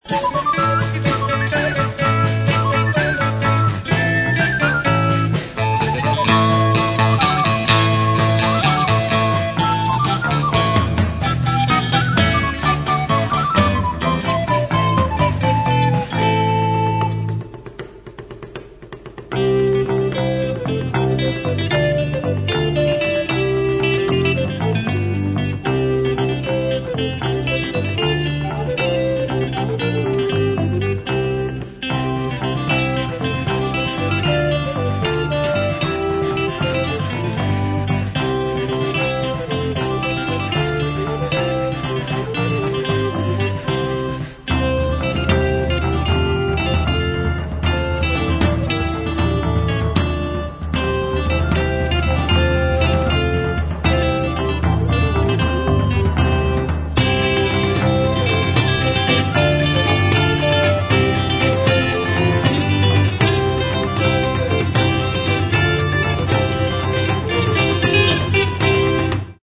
Stereo, 1:08, 56 Khz, (file size: 475 Kb).